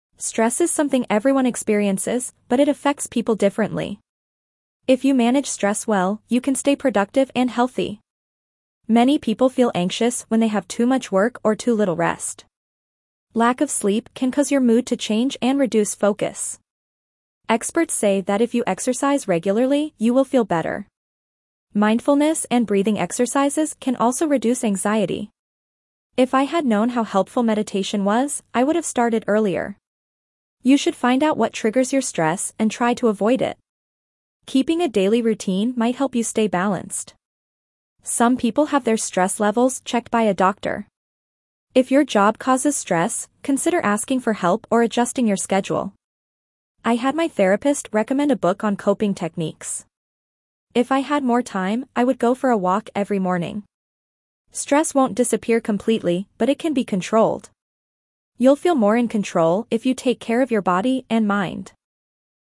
5.-B2-Dictation-Managing-Stress.mp3